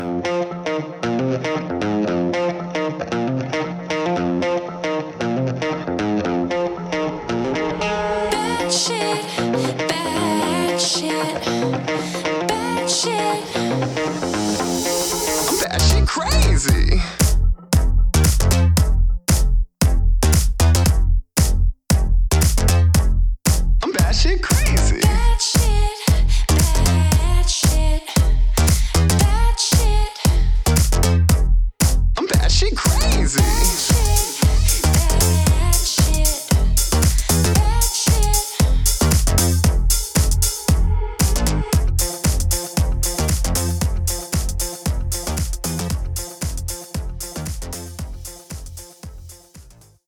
эл. гитара